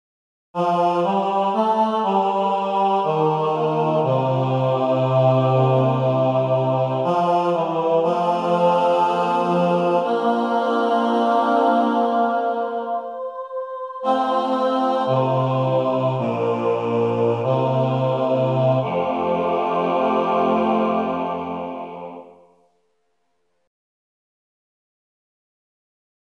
Key written in: F Major
Type: SATB